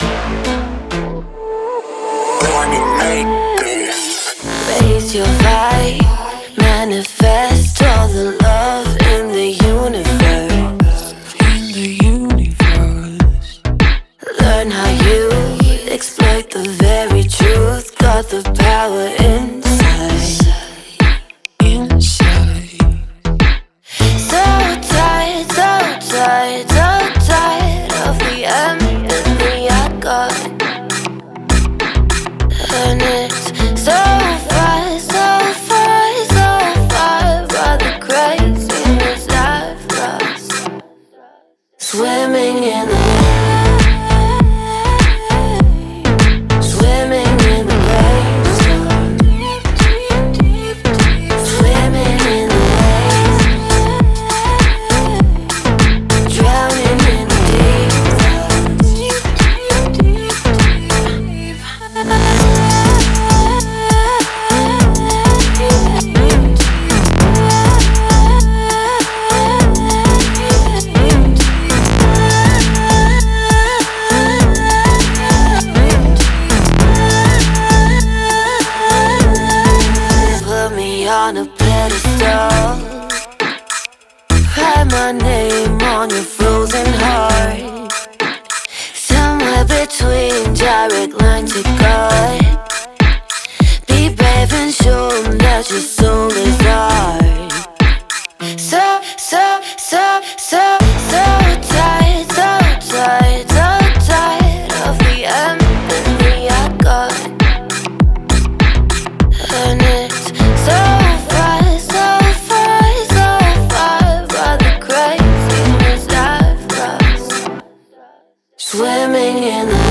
завораживающая электронная композиция